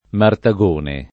[ marta g1 ne ]